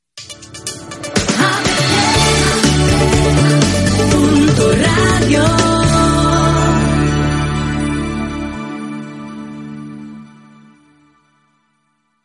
Indicatiu cantat.